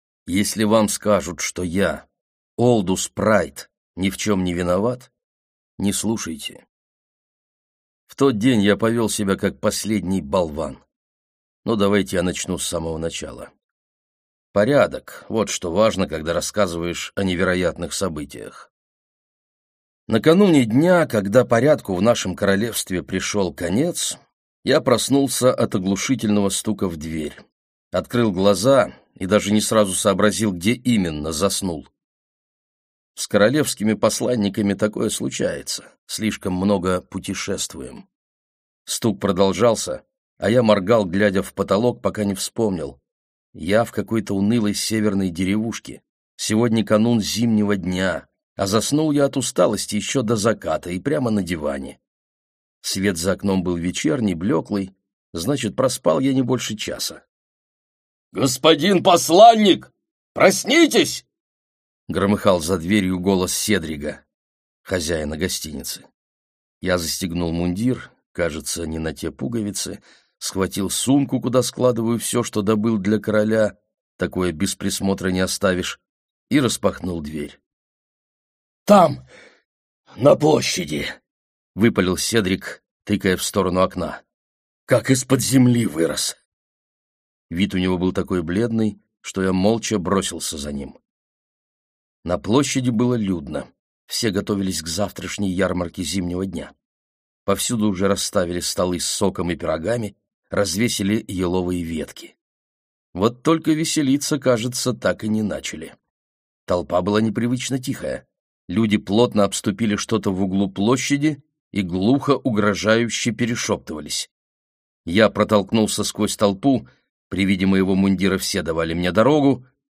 Аудиокнига Дарители. Дар огня | Библиотека аудиокниг